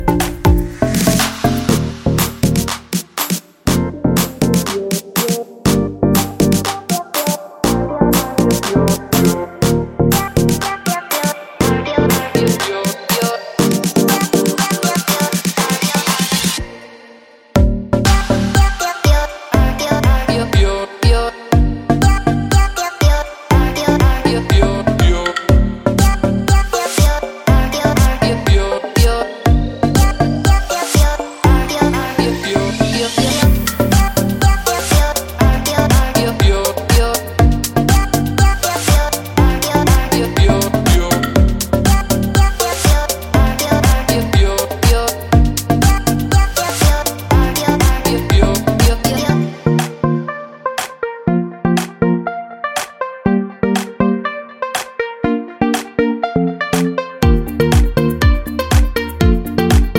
Remix Pop (2010s) 3:17 Buy £1.50